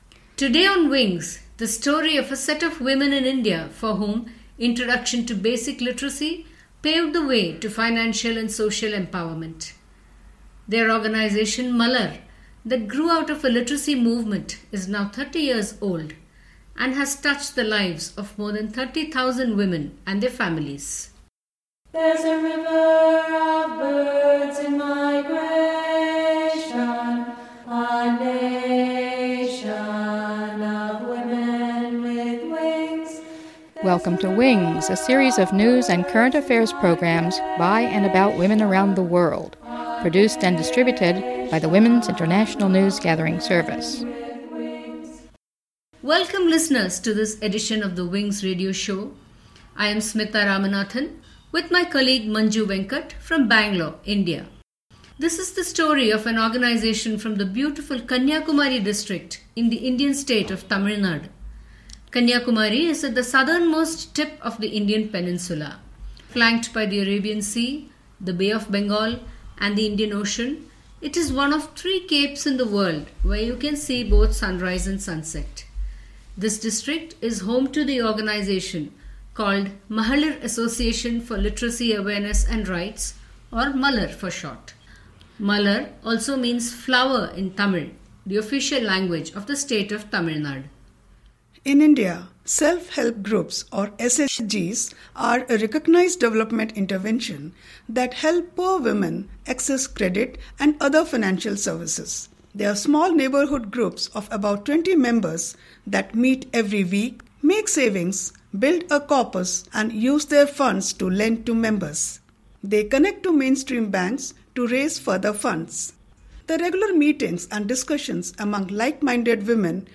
script and interviews